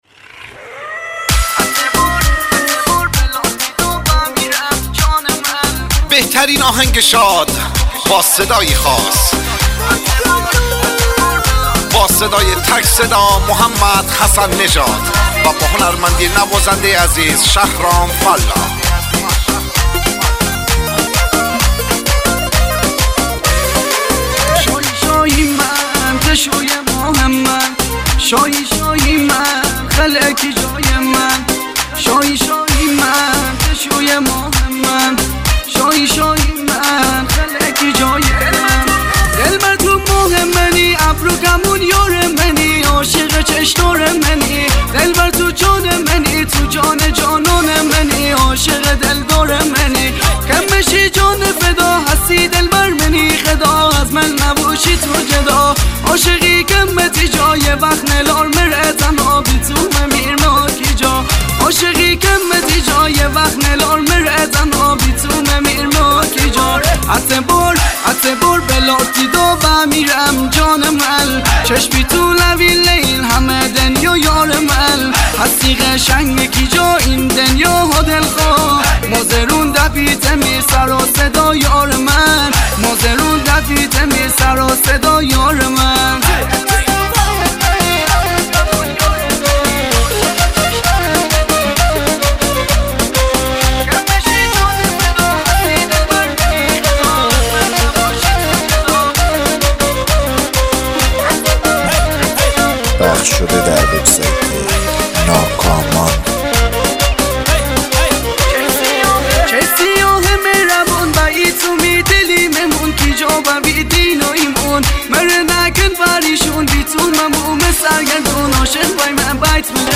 Remix
آهنگ مازندرانی شاد باحال